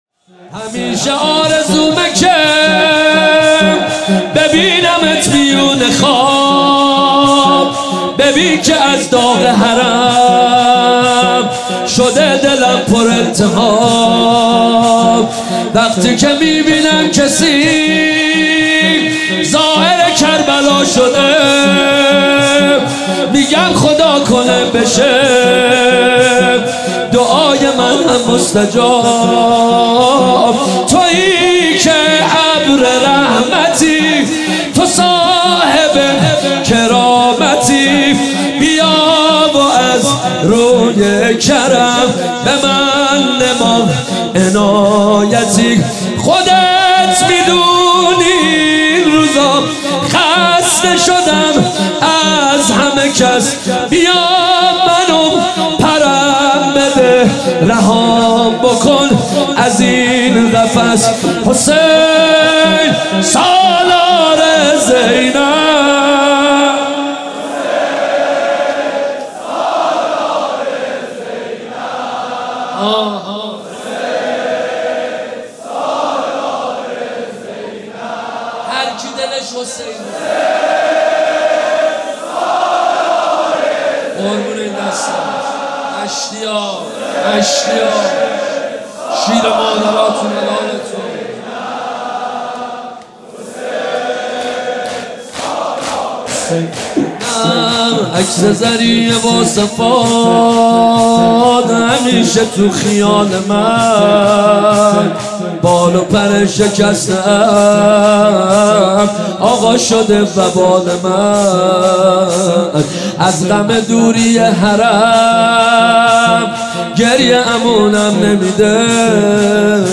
شب اول محرم الحرام 1394 | هیات مکتب الحسین اصفهان
همیشه آرزومه که ببینمت میون خواب | شور | حضرت امام حسین علیه السلام